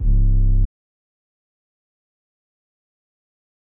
808 (the ends).wav